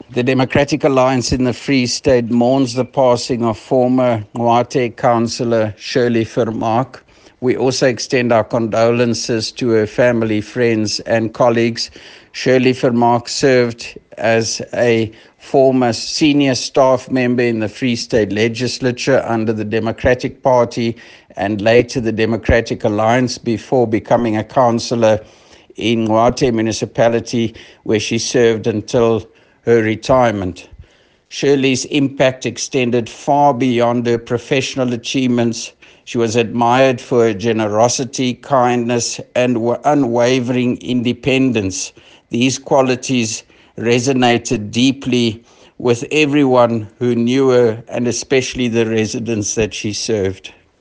English soundbite by Roy Jankielsohn MPL